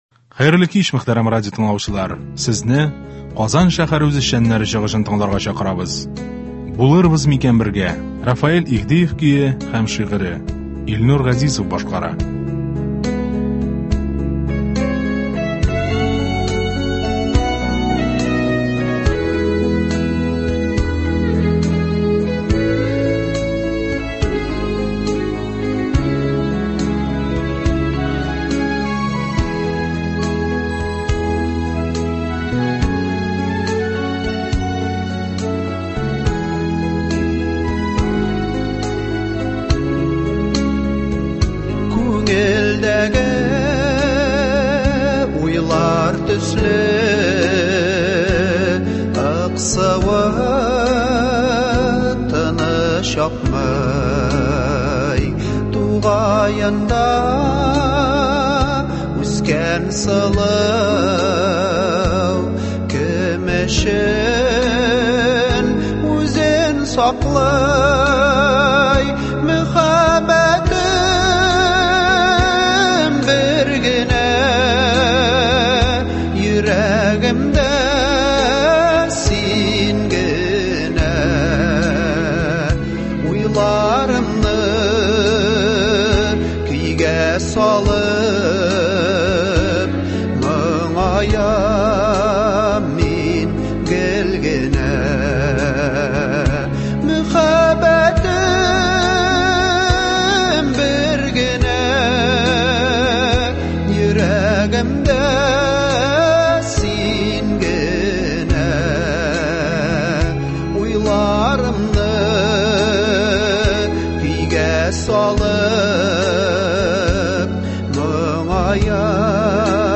Моңлы җырлар юлдаш булсын!